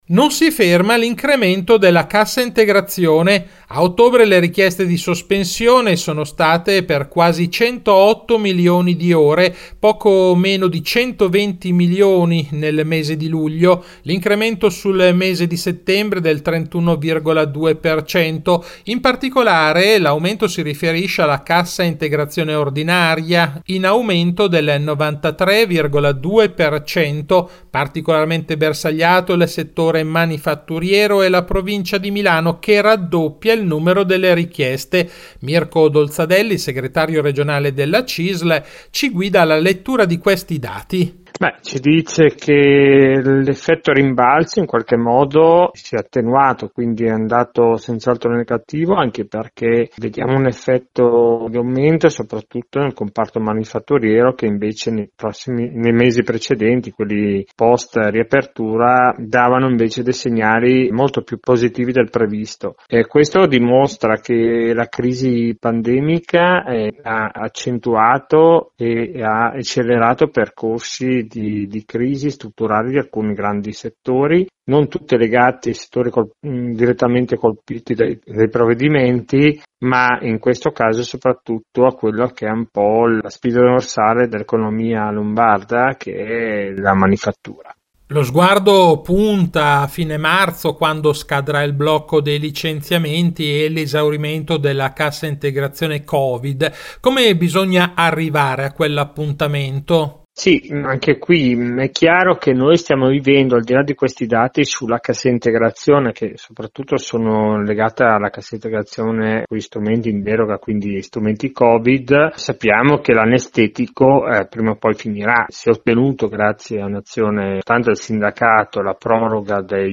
Di seguito la puntata dell’11 dicembre di RadioLavoro, la rubrica d’informazione realizzata in collaborazione con l’ufficio stampa della Cisl Lombardia e in onda ogni quindici giorni su RadioMarconi il venerdì alle 12.20, in replica alle 18.10.